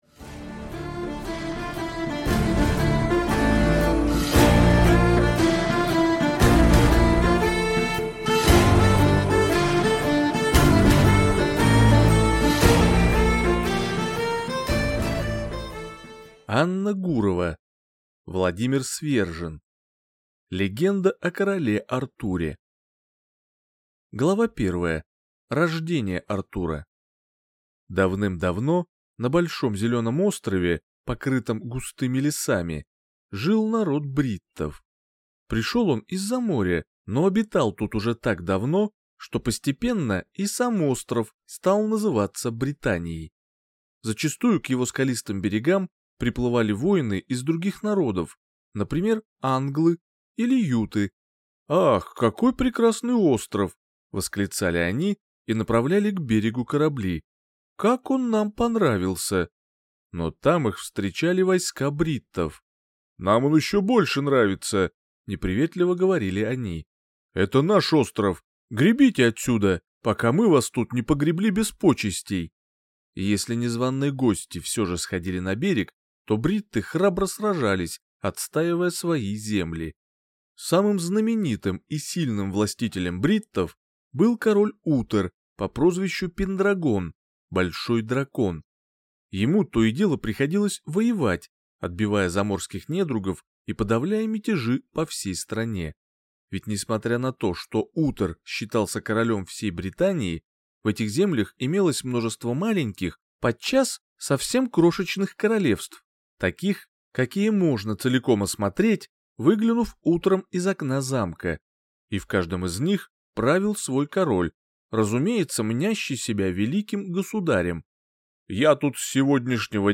Аудиокнига Легенда об Артуре | Библиотека аудиокниг